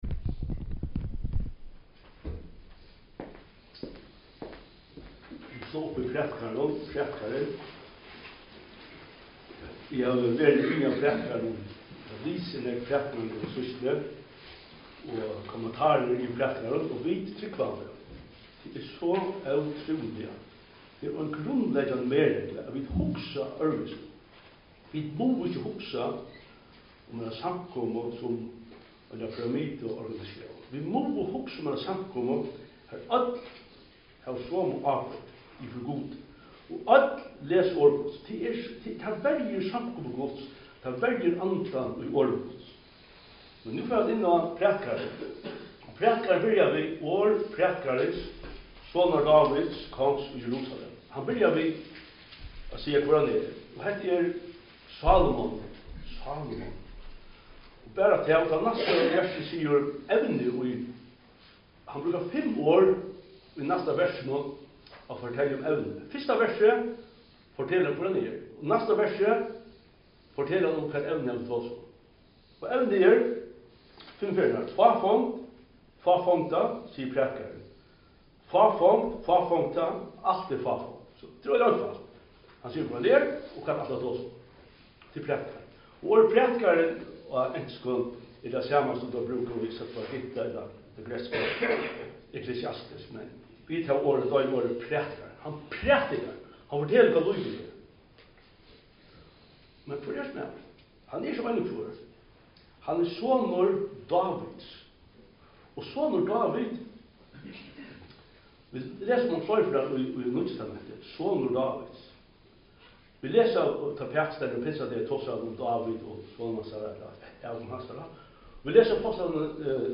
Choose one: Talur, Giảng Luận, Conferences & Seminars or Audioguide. Talur